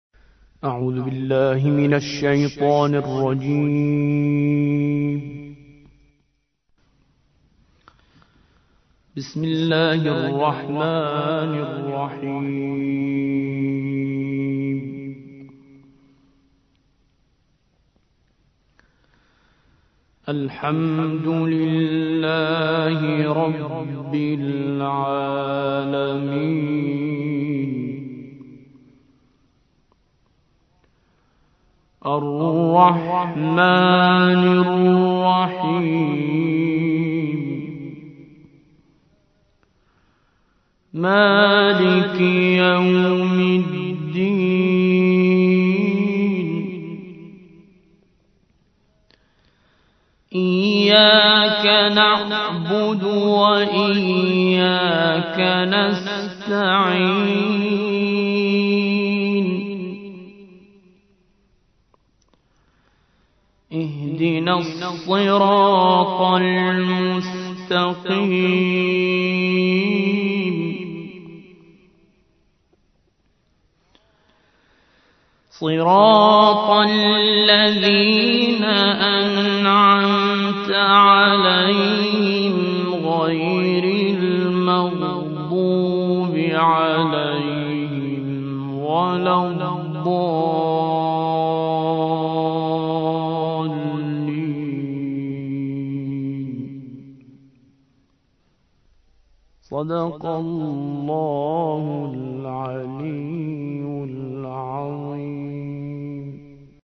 سورة الفاتحة / القارئ